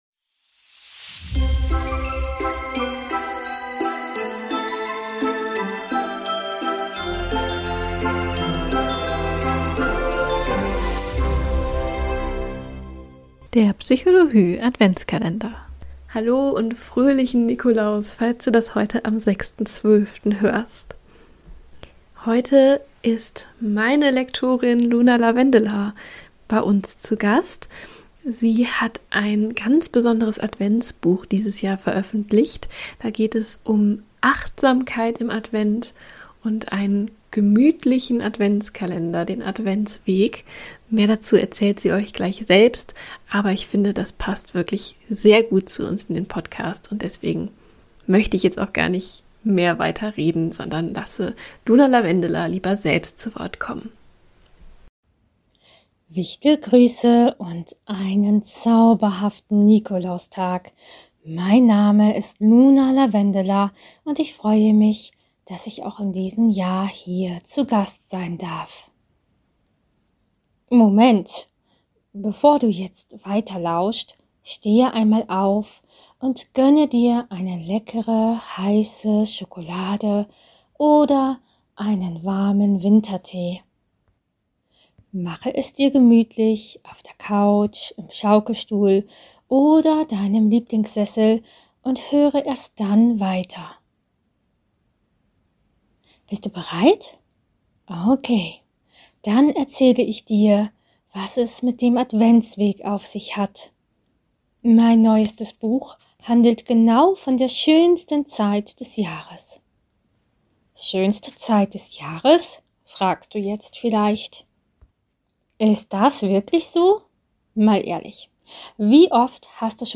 Lesung freuen.